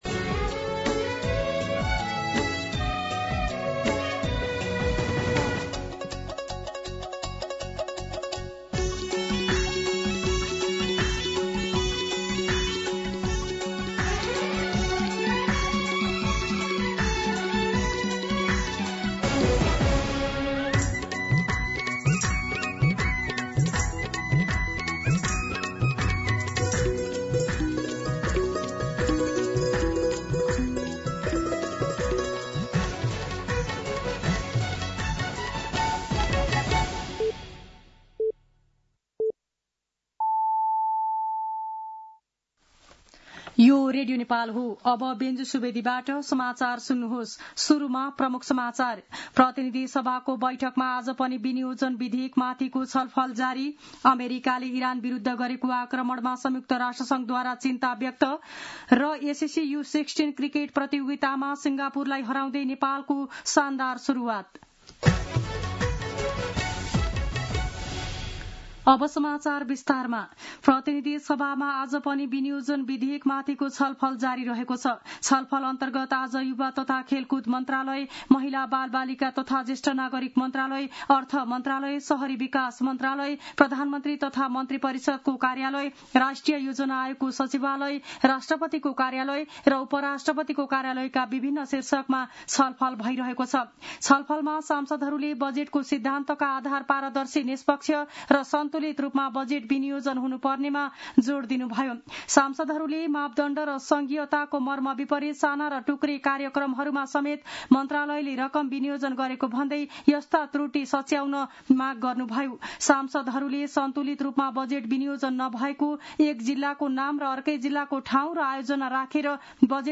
दिउँसो ३ बजेको नेपाली समाचार : ८ असार , २०८२